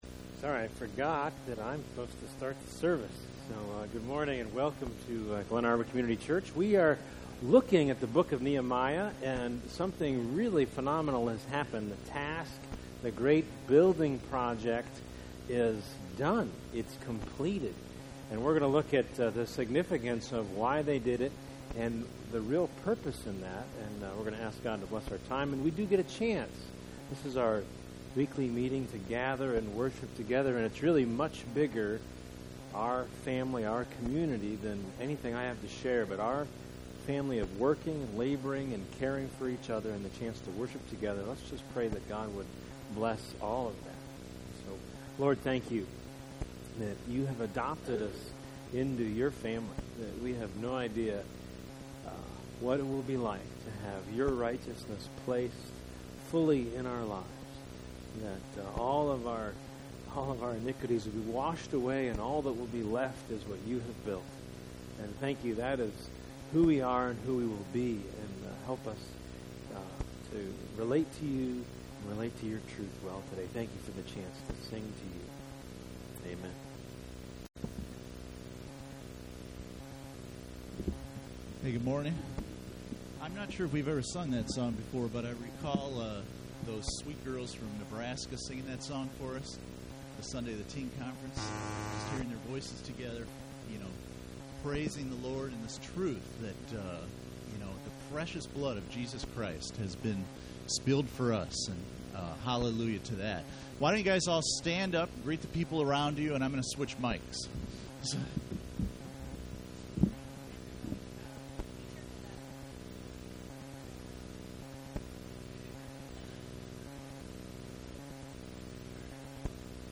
Book of Nehemiah Service Type: Sunday Morning %todo_render% « Nehemiah 7